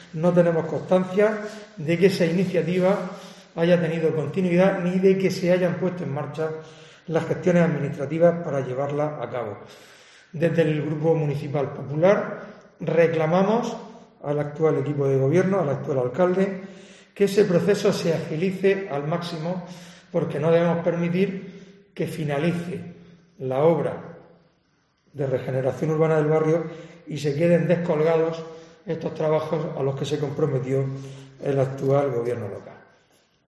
Fulgencio Gil, Portavoz del PP sobre obras de Santa Clara